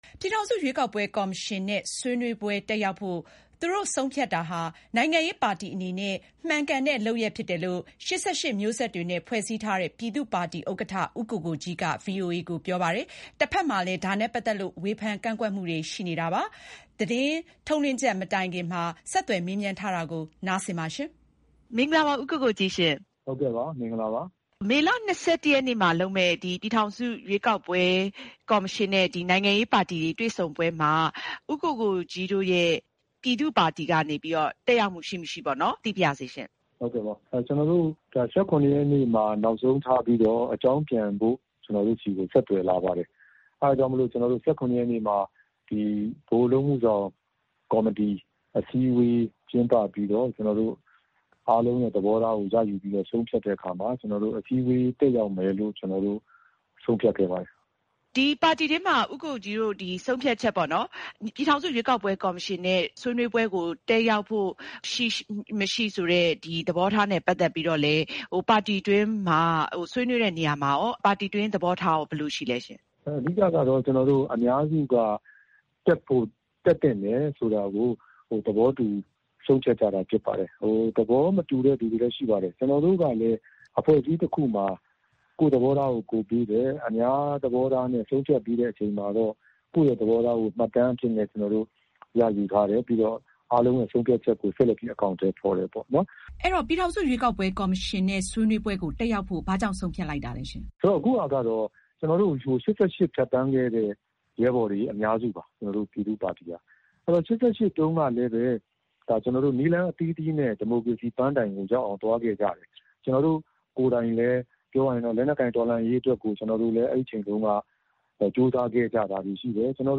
ရွေးကောက်ပွဲကော်မရှင် အစည်းအဝေး ပြည်သူ့ပါတီ ဘာကြောင့်တက်ရောက်မလဲ (ဦးကိုကိုကြီးနှင့် မေးမြန်းချက်)